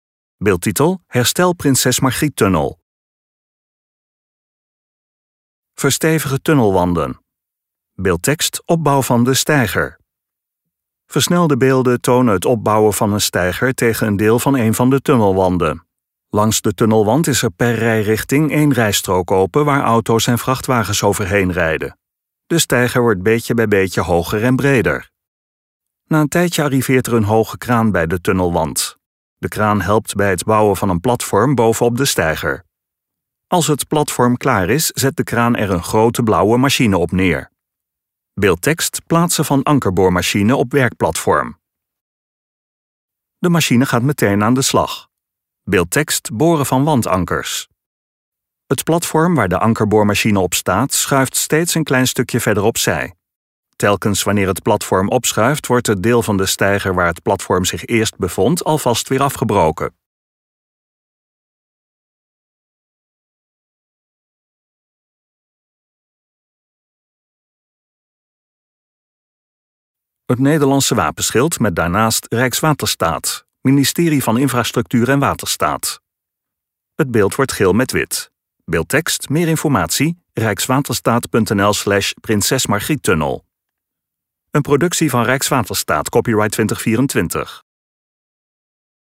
STEVIGE MUZIEK GEVOLGD DOOR DRUKKE MUZIEK Bij de Prinses Margriettunnel in Friesland zijn we op dit moment druk bezig.